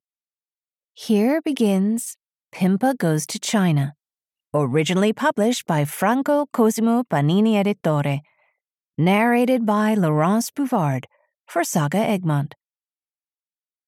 Pimpa Goes to China (EN) audiokniha
Ukázka z knihy